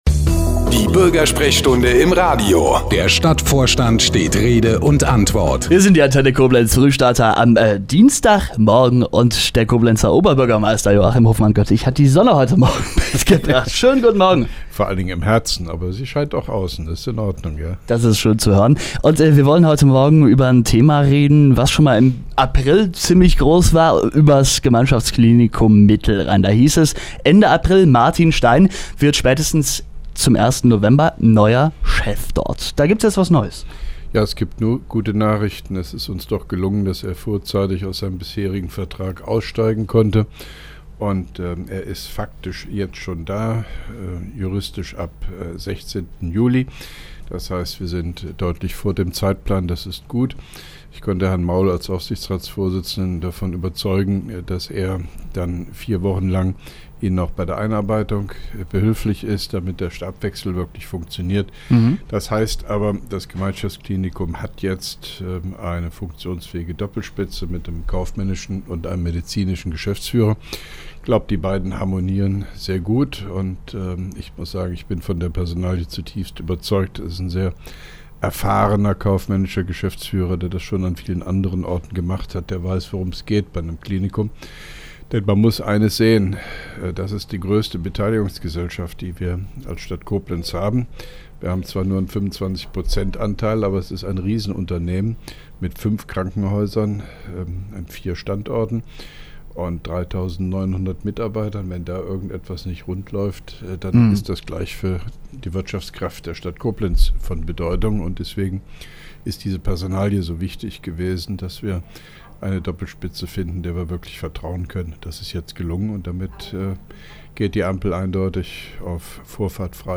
Geschrieben in Interviews/Gespräche JoHo, Medien/Presse zu JoHo, RadioBürgersprechstunde OB von joho | Keine Kommentare